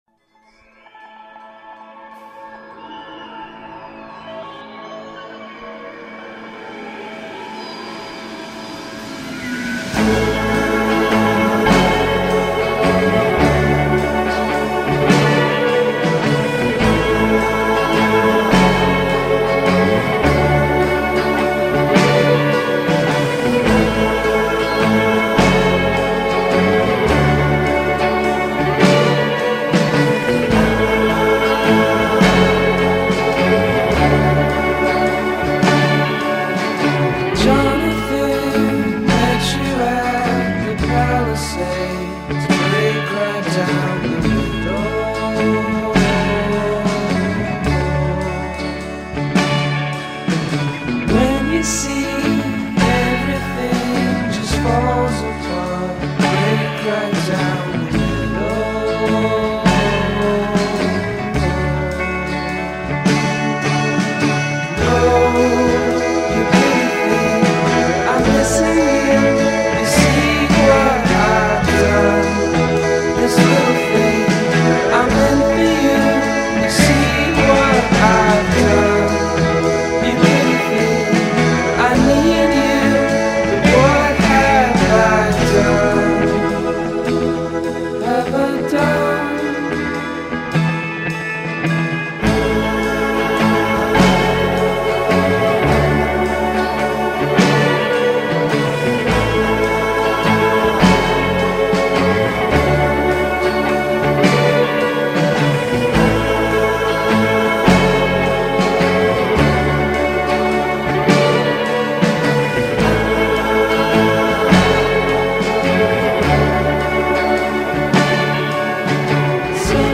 indie pop band